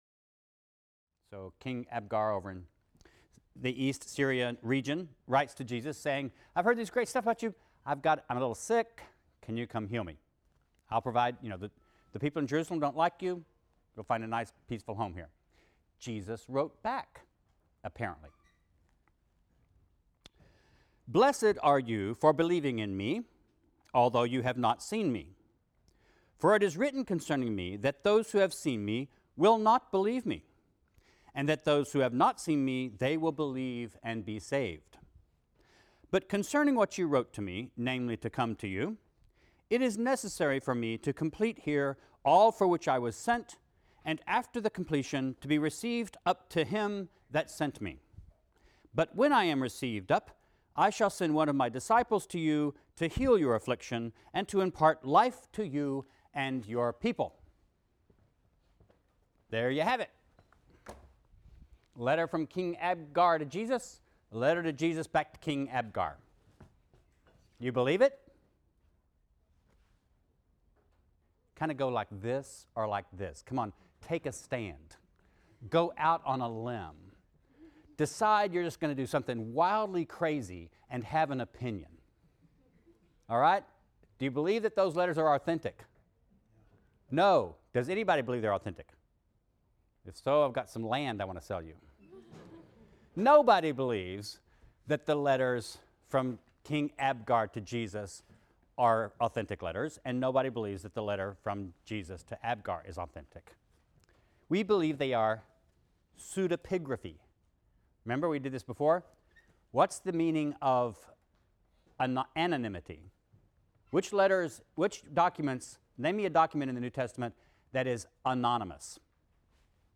RLST 152 - Lecture 17 - Paul’s Disciples | Open Yale Courses